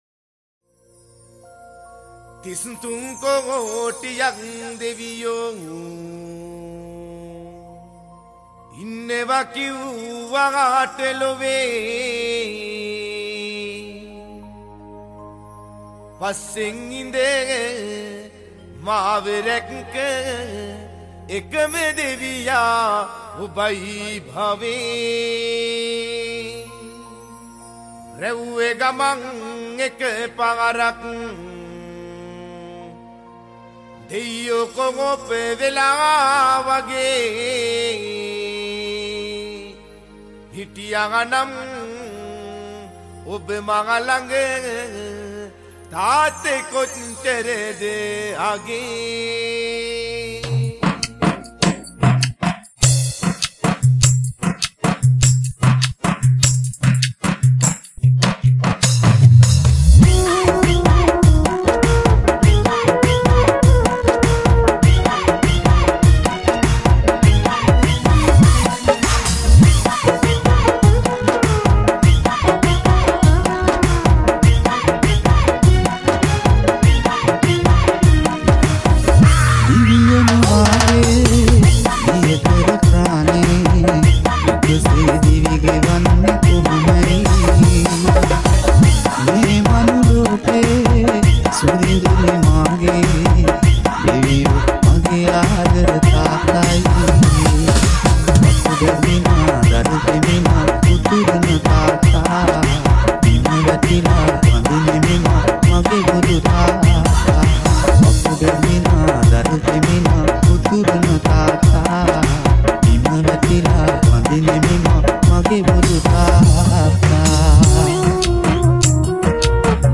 High quality Sri Lankan remix MP3 (8.3).